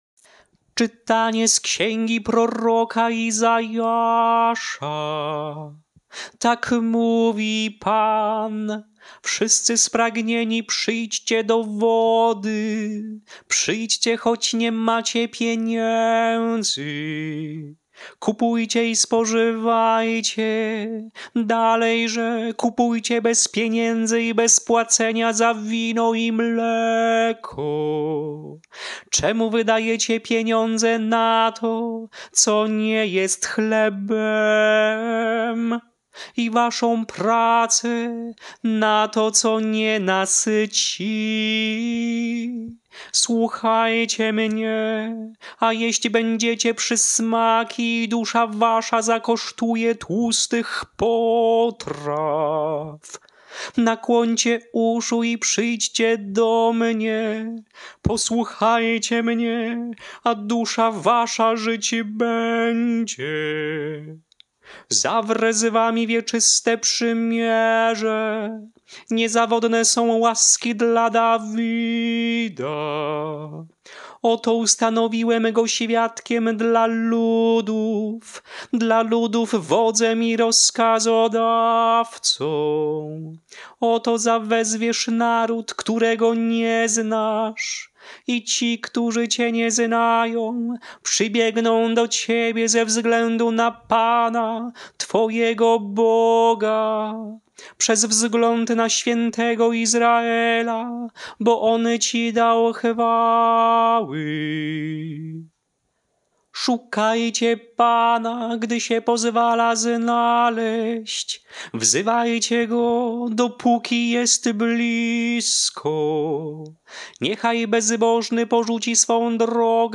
Śpiewane lekcje mszalne – Święto Chrztu Pańskiego
Melodie lekcji mszalnych przed Ewangelią na Święto Chrztu Pańskiego:
Chrzest-Panski-ton-proroctwa.mp3